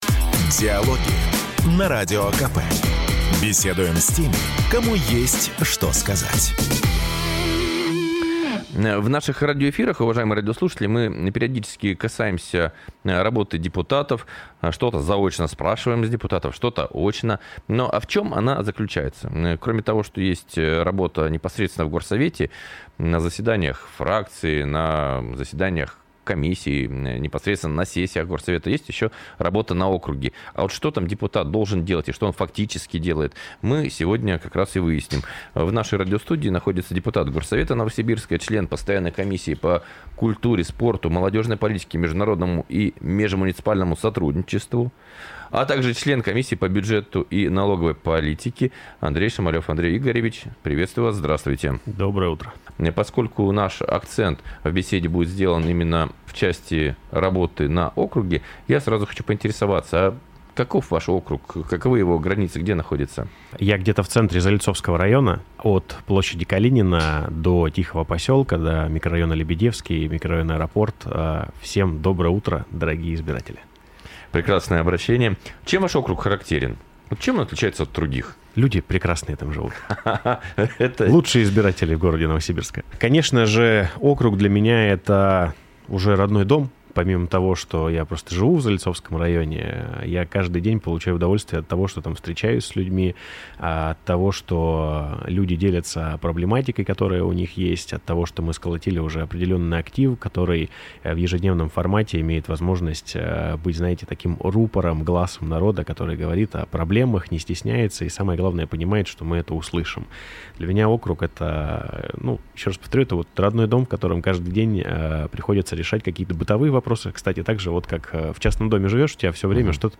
Запись программы, транслированной радио "Комсомольская правда" 26 ноября 2025 года Дата: 26.11.2025 Источник информации: радио "Комсомольская правда" Упомянутые депутаты: Шамалев Андрей Игоревич Аудио: Загрузить